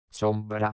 Primo tipo
Si pronunciano chiudendo le labbra.